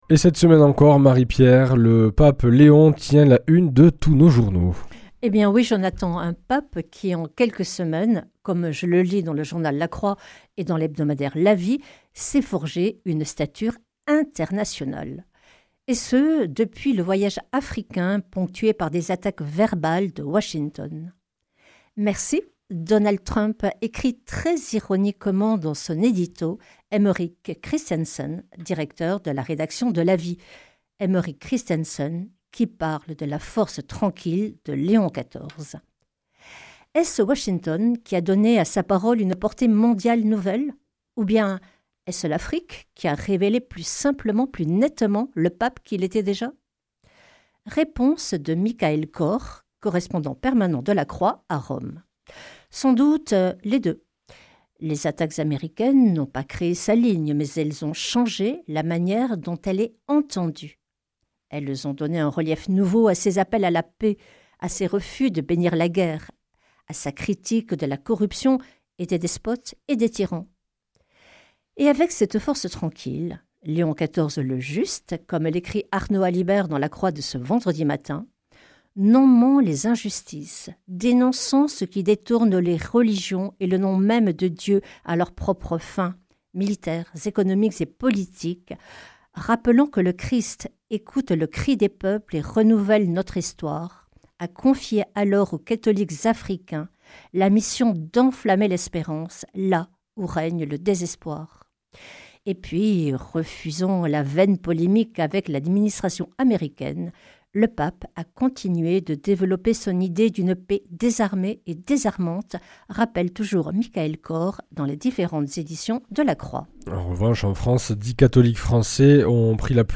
Revue de presse
Une émission présentée par